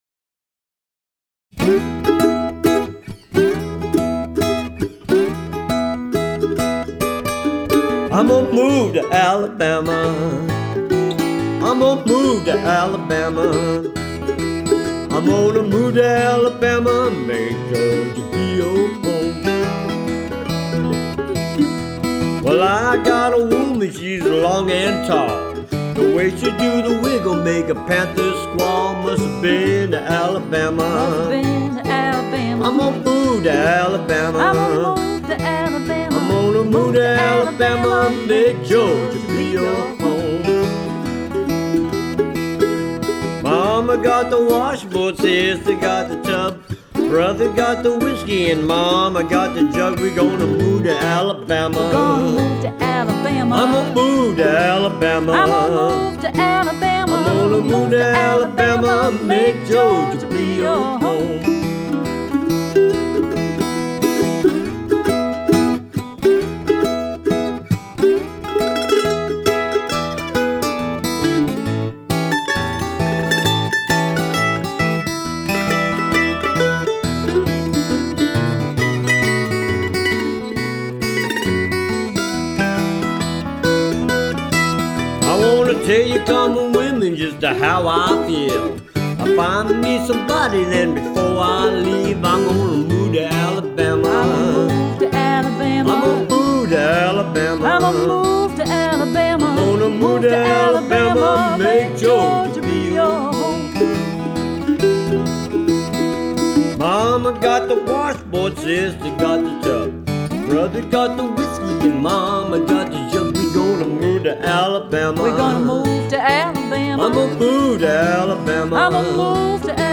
Acoustic Blues and Beyond